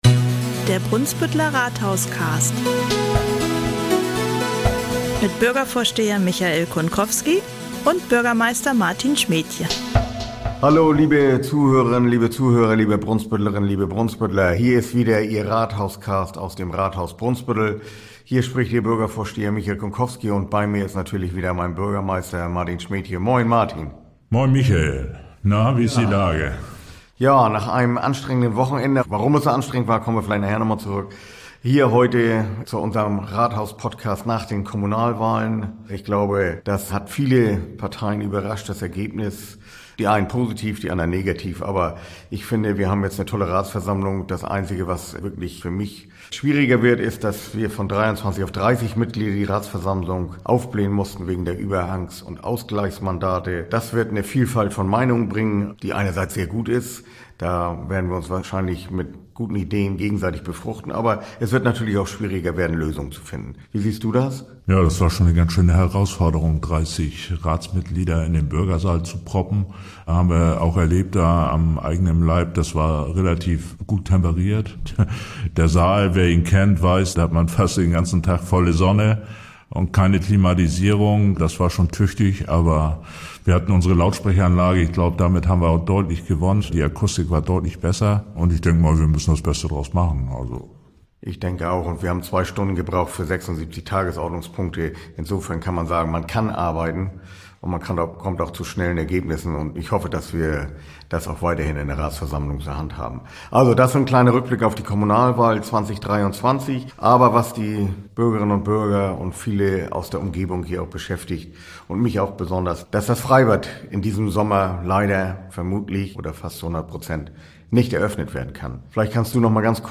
In dieser Folge plaudern Bürgervorsteher Michael Kunkowski und Bürgermeister Martin Schmedtje unter anderem über die Problematik Freibad Ulitzhörn. Wo hakt es da genau, und kann es in diesem Sommer eventuell doch noch eine Eröffnung geben?